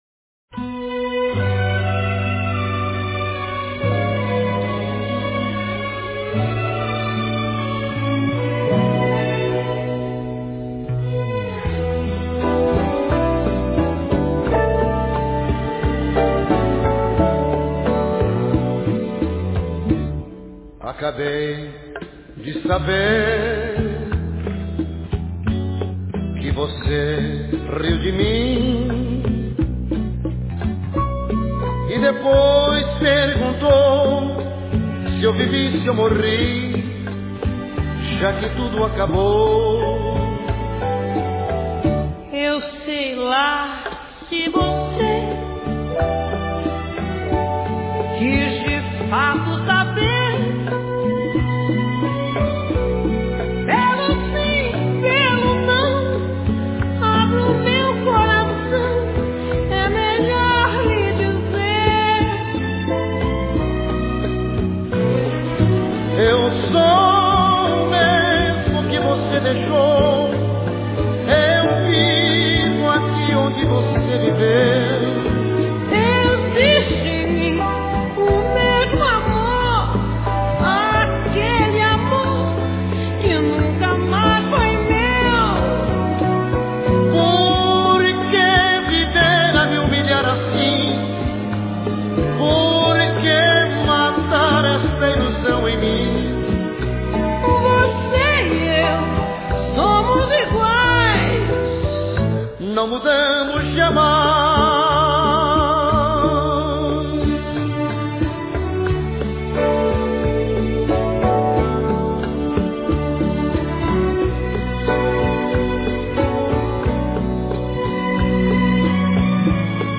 Trazia com ele, o samba canção